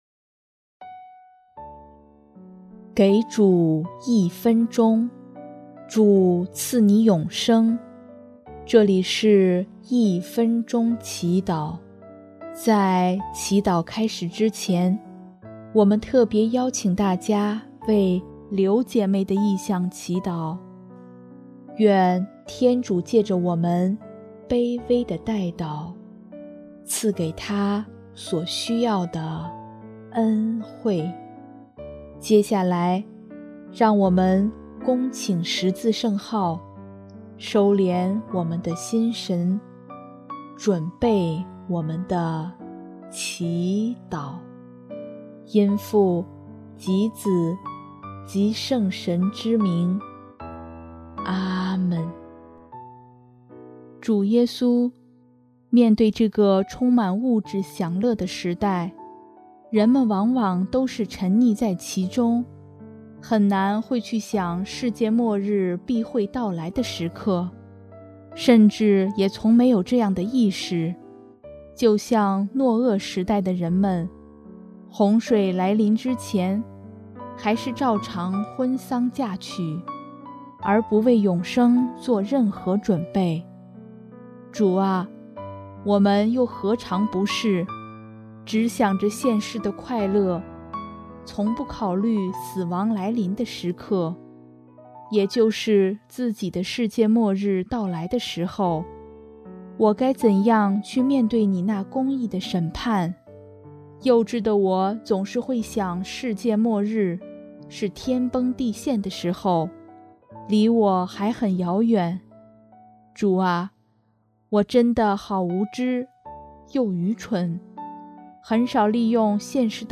【一分钟祈祷】|11月27日 警醒等待
音乐： 主日赞歌《悔改》